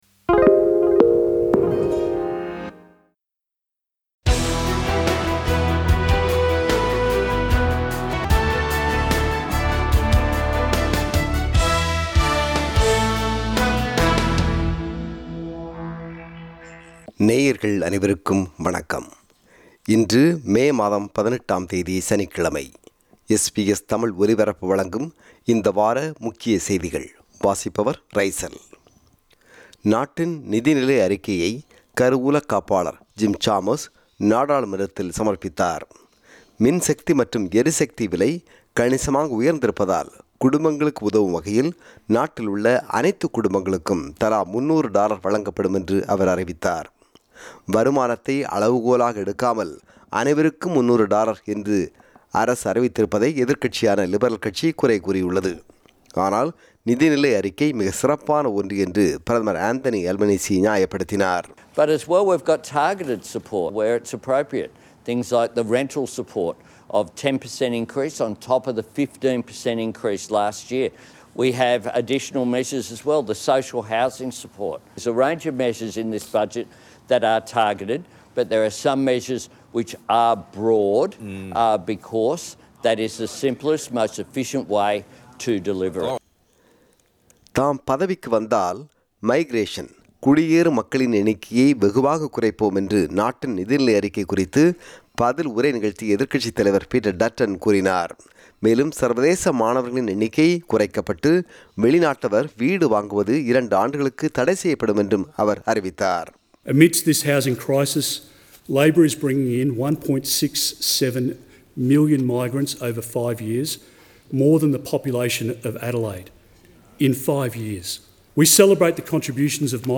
இந்த வார முக்கிய செய்திகள்